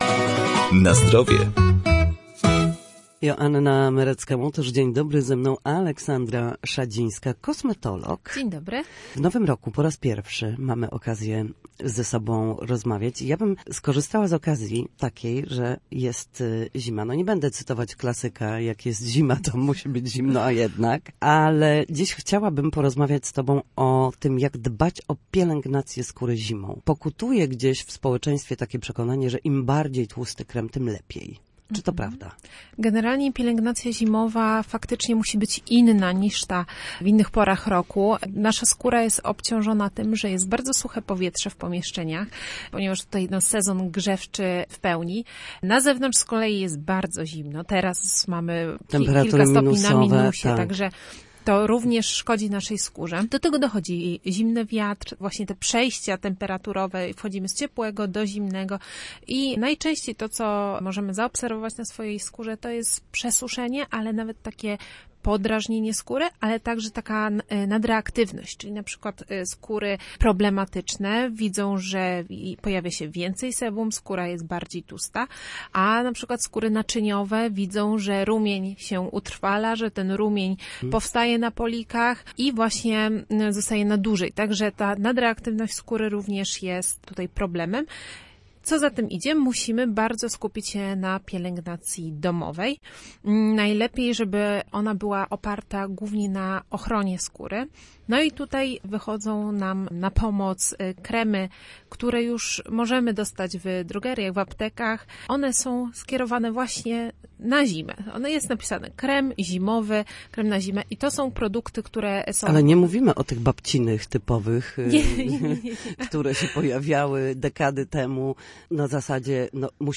Na antenie Studia Słupsk sprawdzamy sposoby na powrót do formy po chorobach i urazach.